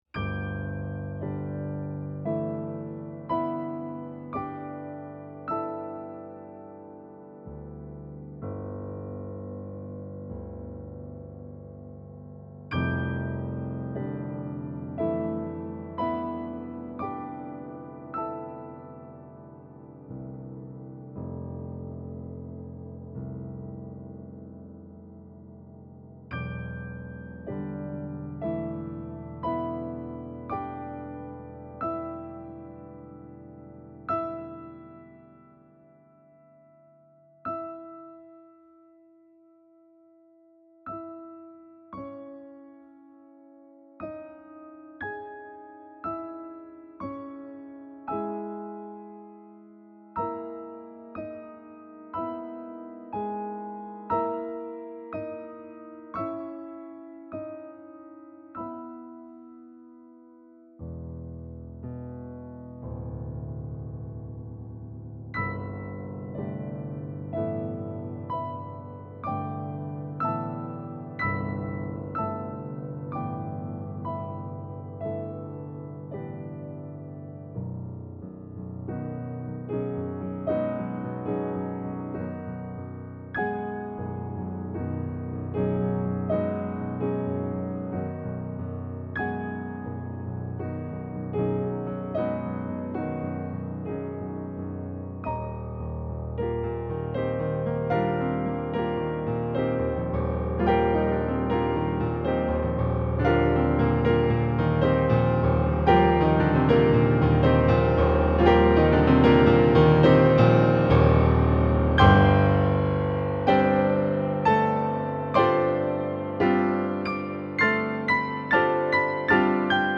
He gave me his MIDI file and I did this with a modified NYD Player preset plus some external reverb and saturation and EQ:
Interestingly, this section (1:40 to 2:30) was the one I found most realistic in Pianoteq.
I find the Pianoteq version smoother with better rendition of the dynamics.